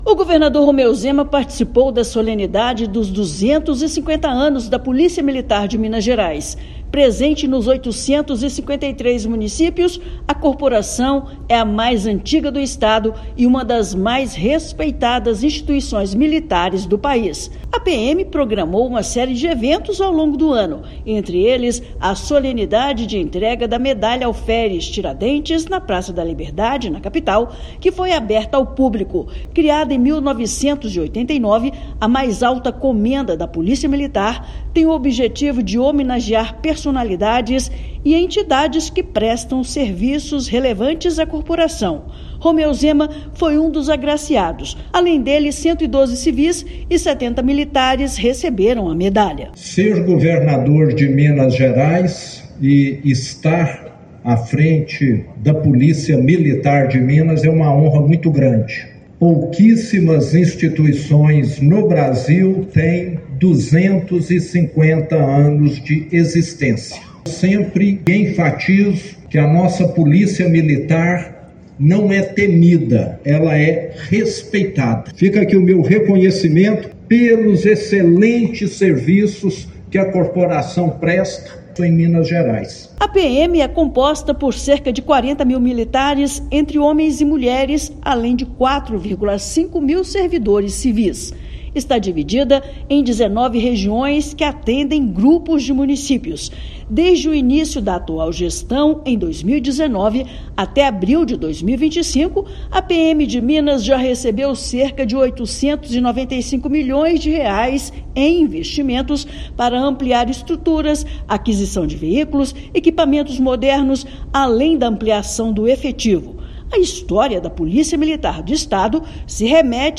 [RÁDIO] Governo do Estado realiza solenidade de 250 anos da Polícia Militar de Minas Gerais
Presente nos 853 municípios mineiros, a corporação é a Polícia Militar mais antiga e uma das mais respeitadas do país. Ouça matéria de rádio.